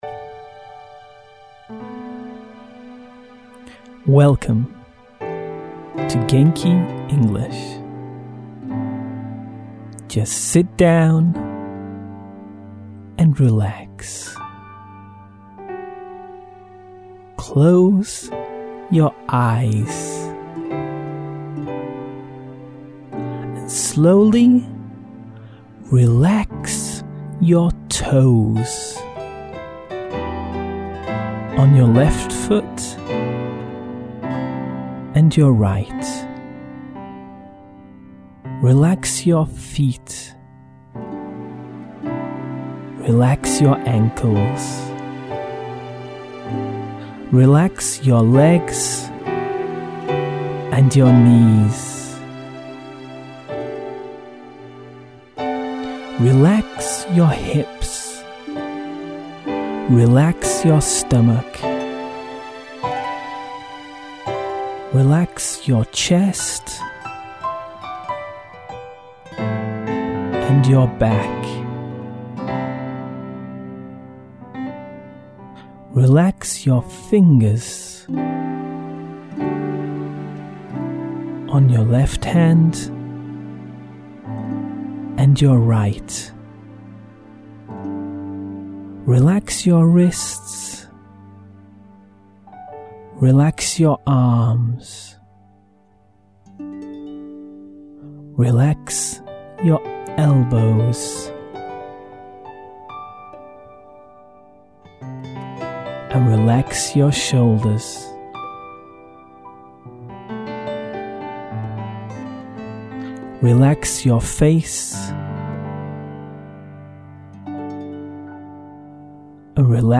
Meditation & Relaxation with English Affirmations.